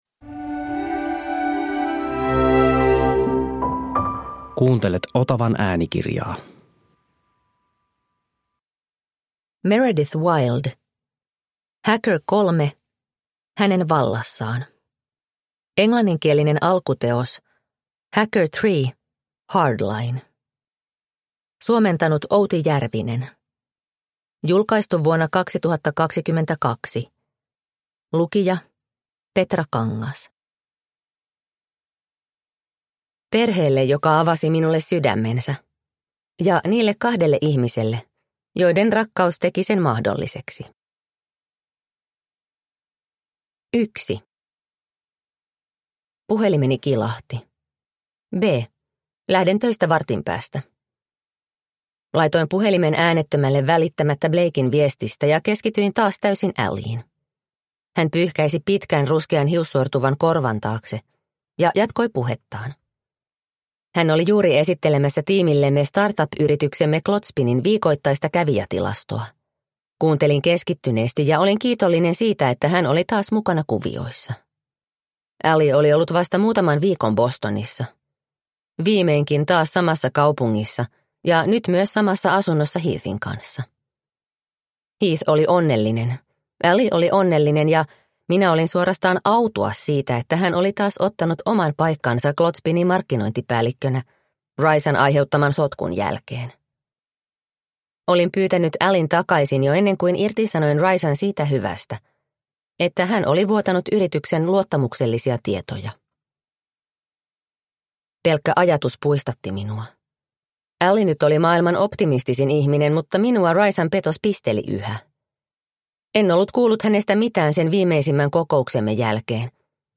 Hacker 3. Hänen vallassaan – Ljudbok – Laddas ner